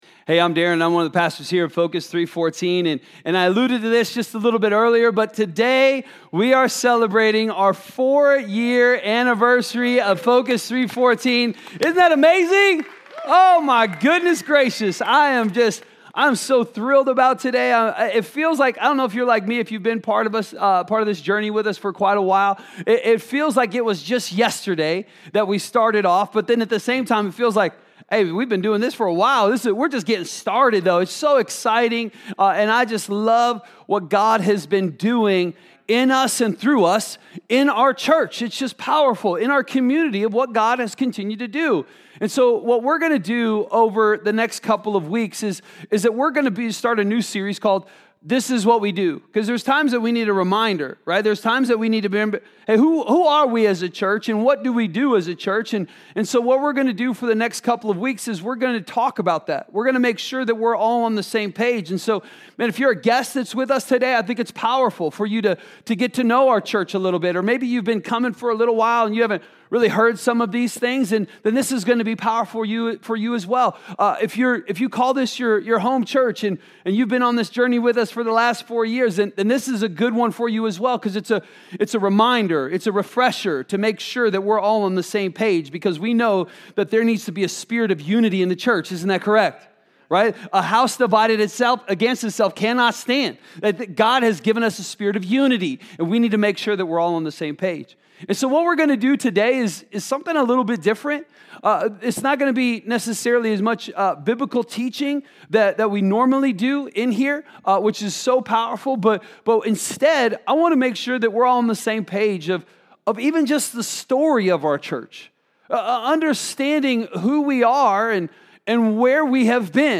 A message from the series "This Is What We Do."